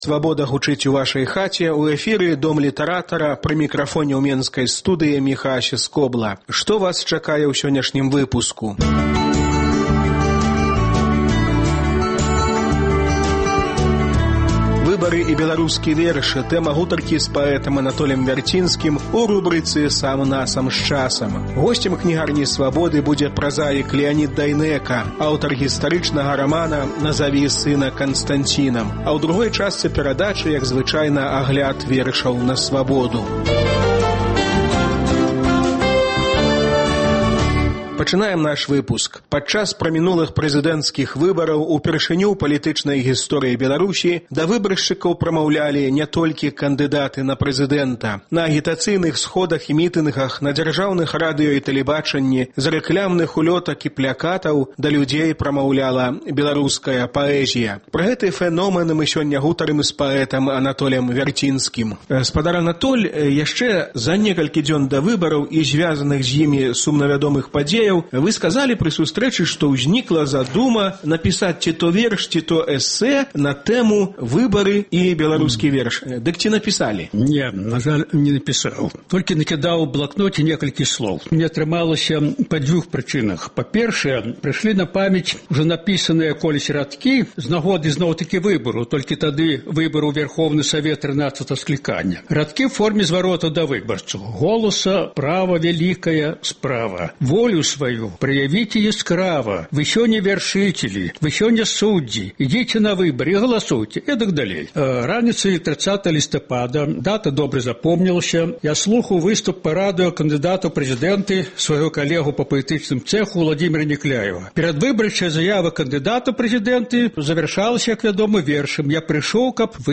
Онлайн- канфэрэнцыя
Онлайн- канфэрэнцыя з кіраўніком Руху "За Свабоду" Аляксандрам Мілінкевічам.